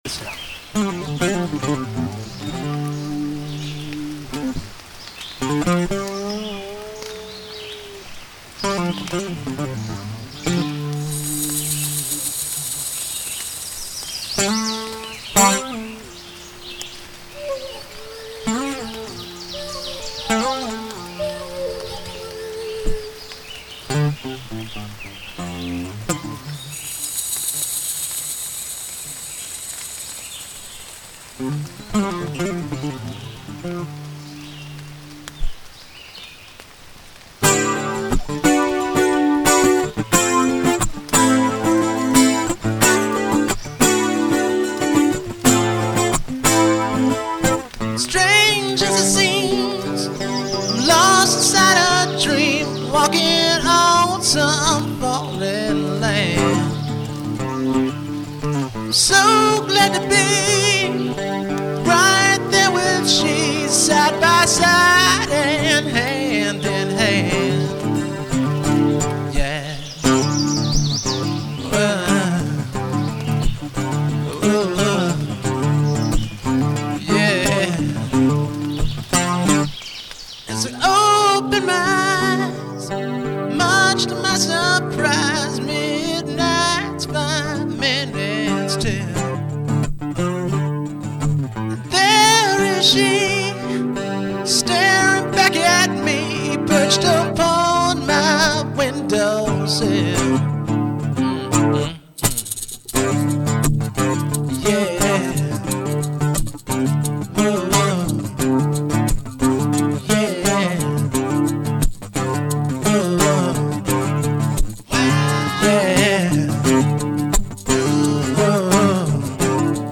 All Instruments & Vocals
Turntablism & Scratches
Raps
Vocals